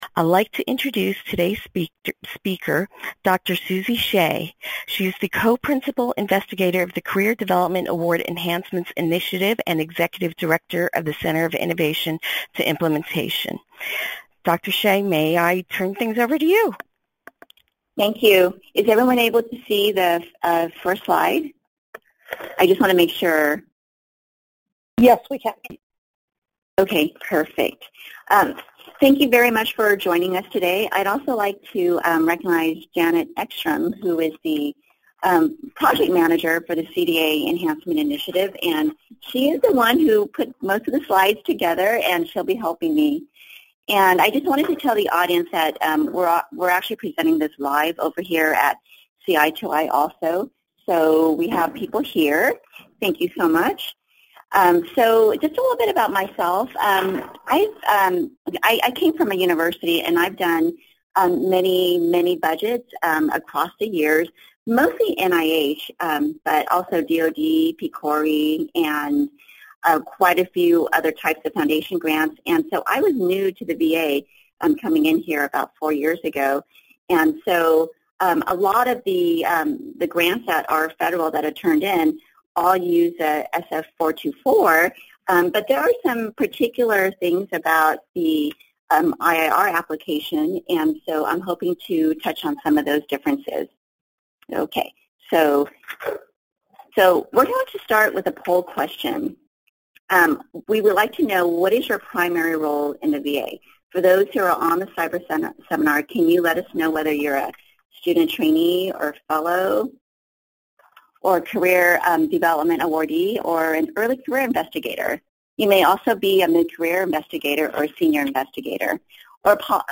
Description: This presentation will provide a detailed overview of how to prepare the budget portion for an IIR grant application. Basic do’s and don’ts will be covered, as well as common mistakes.